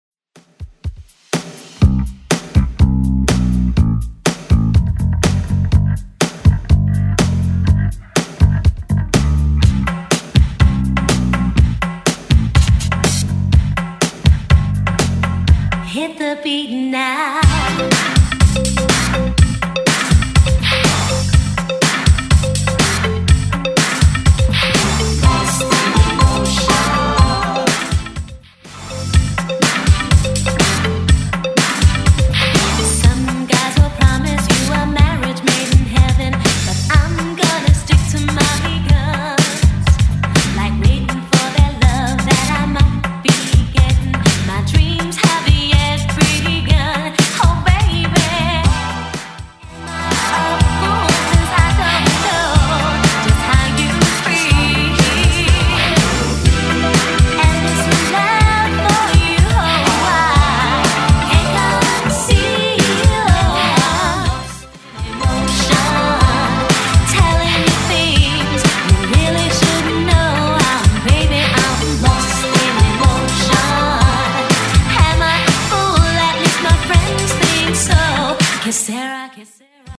Genre: 90's Version: Clean BPM: 87